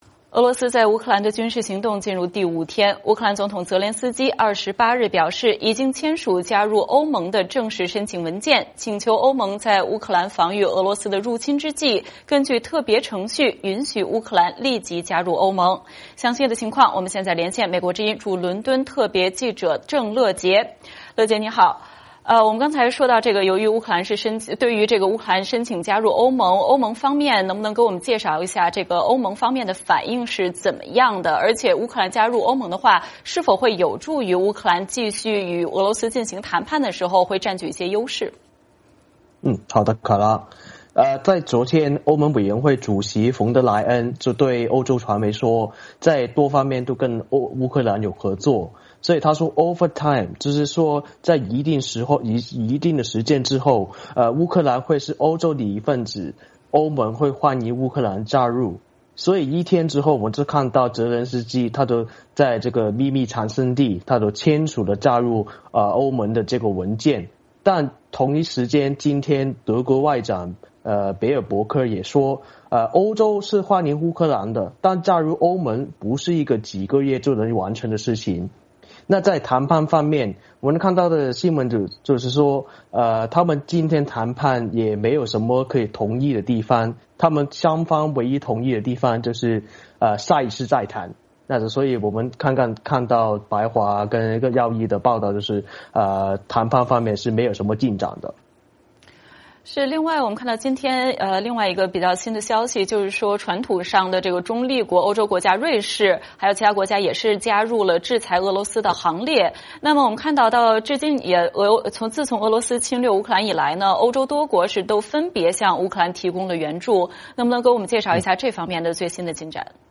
VOA连线: 欧盟支持乌克兰加入 欧洲中立国加入制裁俄罗斯